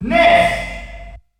The announcer saying Ness' name in German releases of Super Smash Bros.
Ness_German_Announcer_SSB.wav